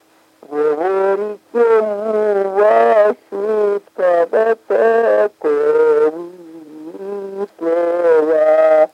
Оканье (полное оканье, свойственное Поморской группе севернорусского наречия – это различение гласных фонем /о/ и /а/ во всех безударных слогах)